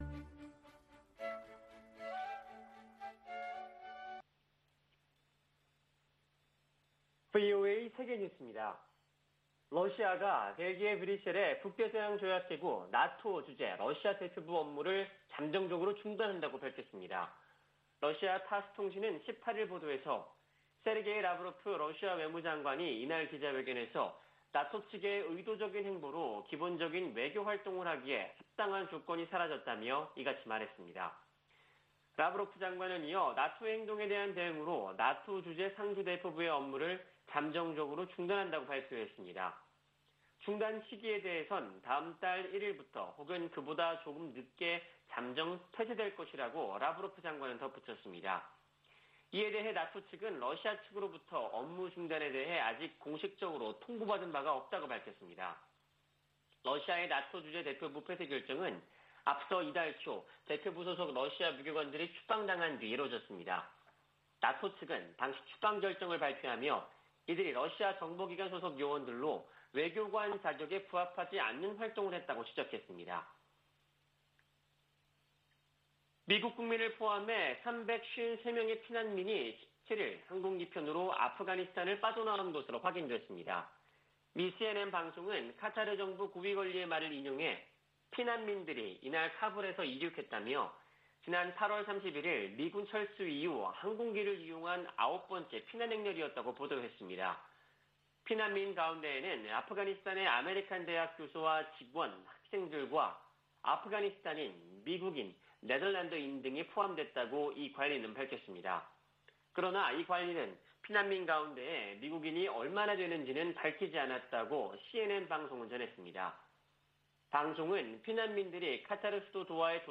VOA 한국어 아침 뉴스 프로그램 '워싱턴 뉴스 광장' 2021년 10월 19일 방송입니다. 미군 정보당국이 새 보고서에서 북한이 장거리 미사일 시험 발사와 핵실험을 재개할 수 있다고 전망했습니다. 미 국무부는 한반도의 완전한 비핵화 목표를 진전시키기 위해 한국, 일본과 긴밀한 협력을 지속하고 있다고 밝혔습니다.